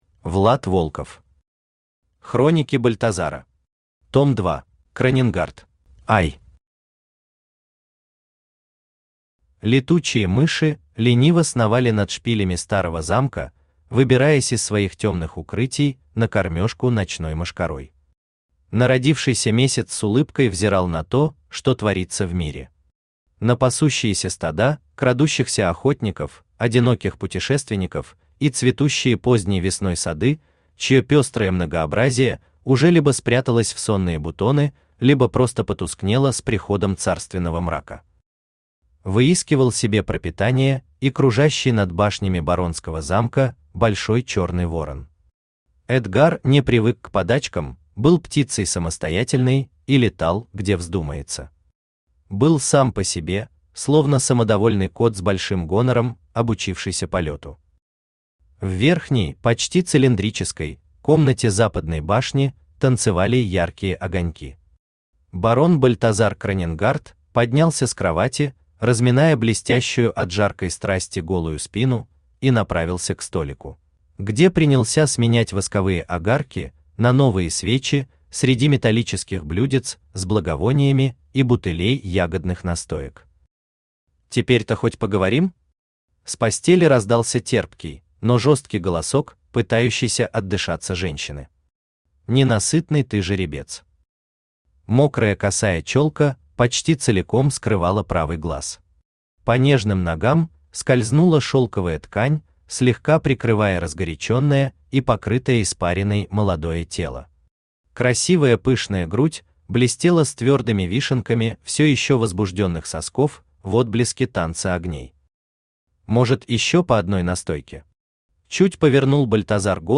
Аудиокнига Хроники Бальтазара. Том 2 | Библиотека аудиокниг
Aудиокнига Хроники Бальтазара. Том 2 Автор Влад Волков Читает аудиокнигу Авточтец ЛитРес.